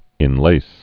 (ĭn-lās)